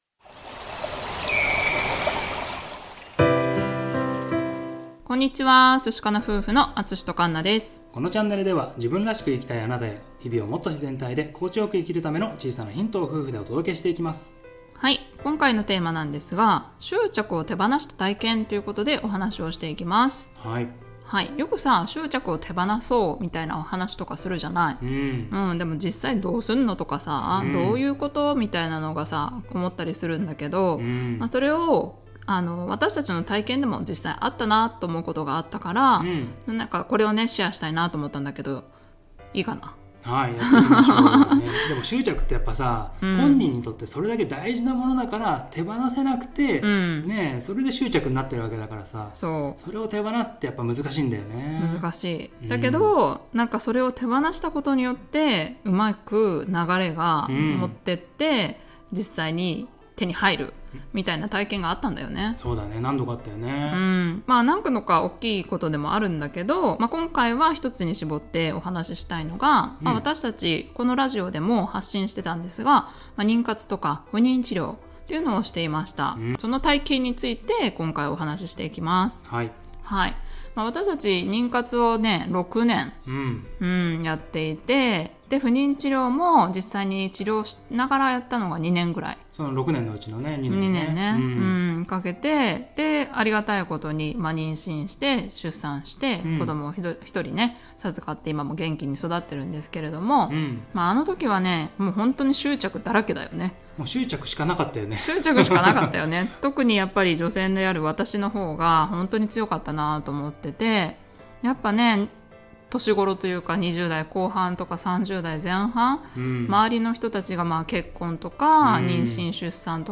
このエピソードでは、執着を手放した体験を私たち夫婦の妊活・不妊治療をした経験からお話しています。